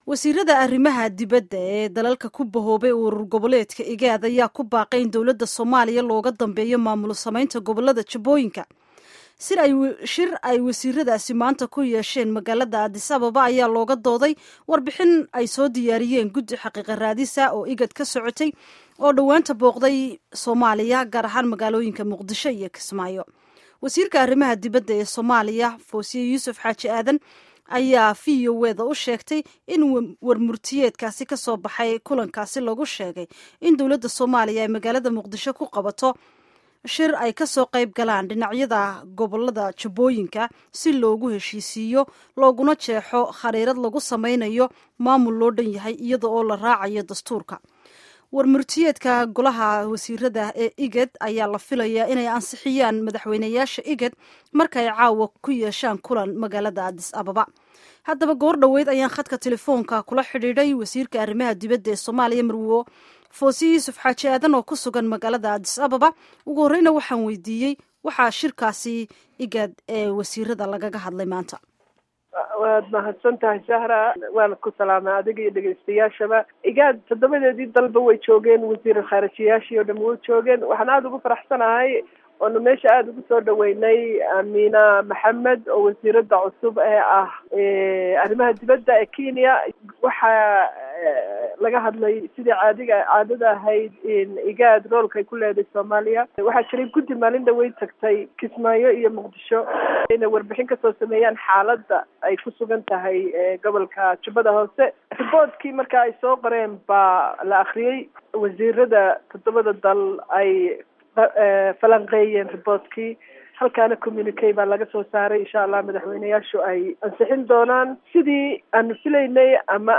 Waraysiga wasiirka arrimaha dibadda ee Soomaaliya